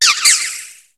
Cri de Bombydou dans Pokémon HOME.